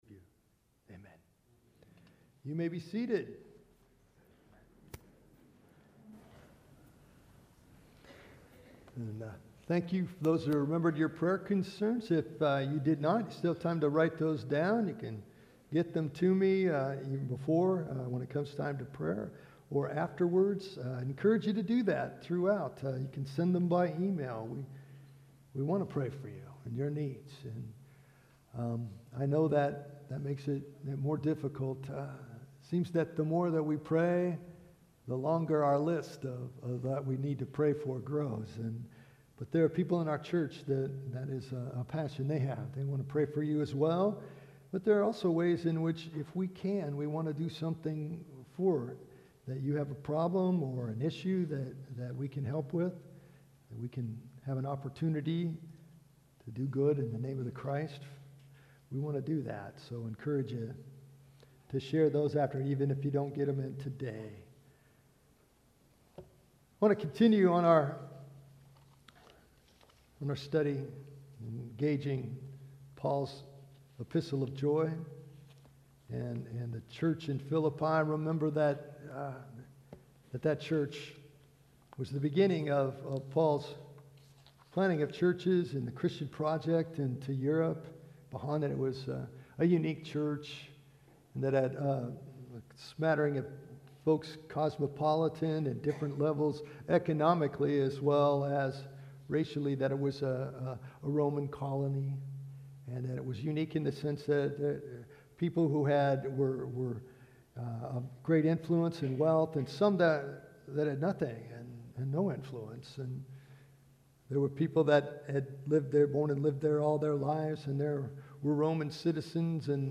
Today we continue our sermon series on “Joy.”